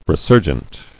[re·sur·gent]